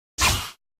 SFX_Dash.mp3